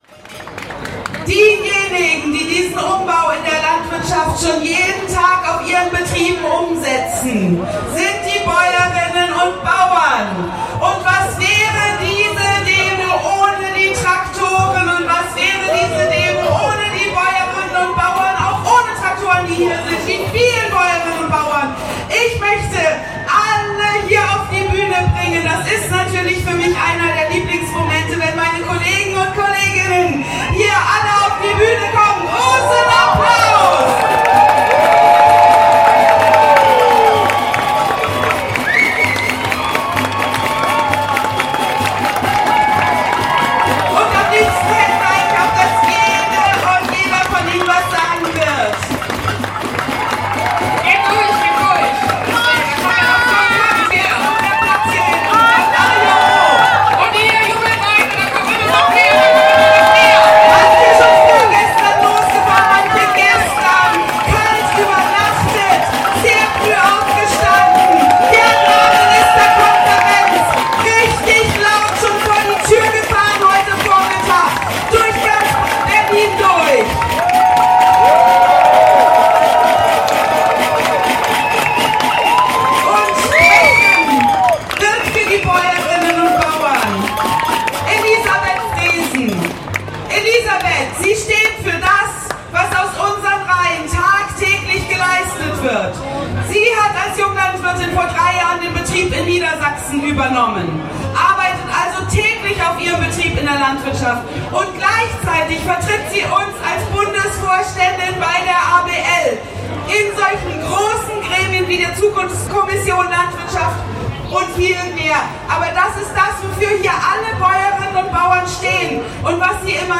Abschlusskundgebung
Der zweite Teil des Bühnenprogramms